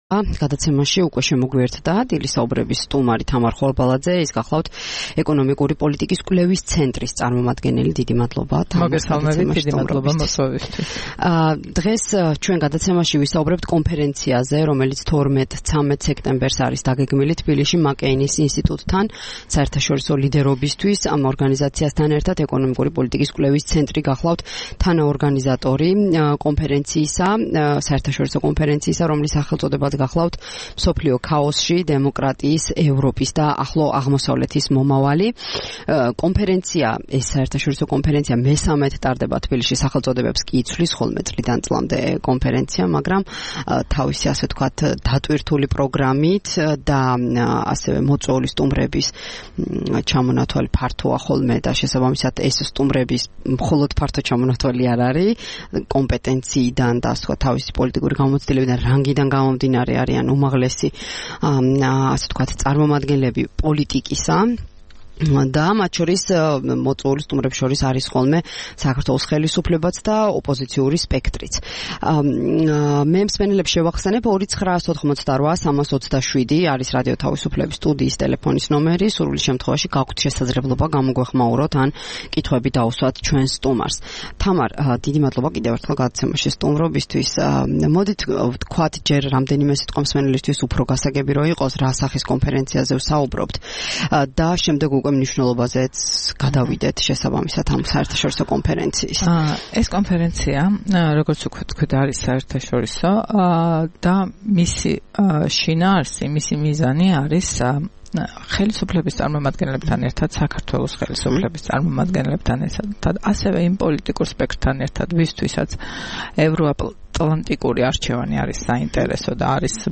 11 სექტემბერს რადიო თავისუფლების „დილის საუბრების“ სტუმარი იყო